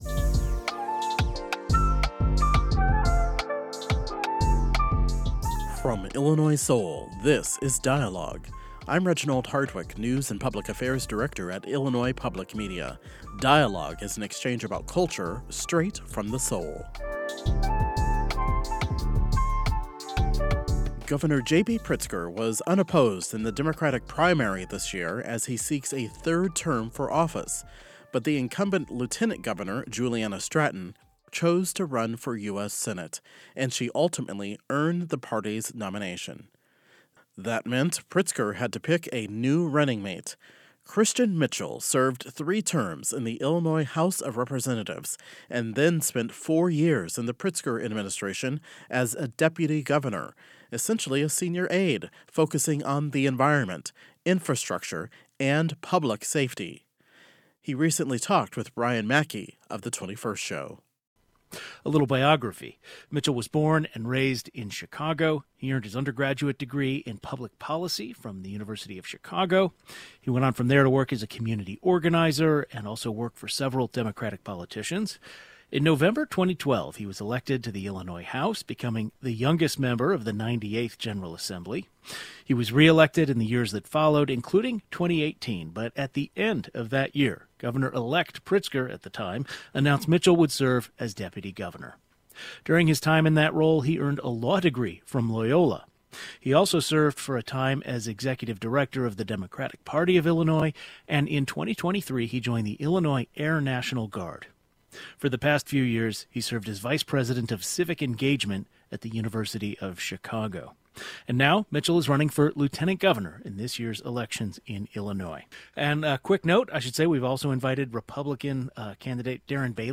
Democratic Lt. Governor candidate Christian Mitchell discusses his background in politics and what he brings to the table. NPR's Ayesha Rascoe speaks with civil rights attorney Benjamin Crump about his debut novel,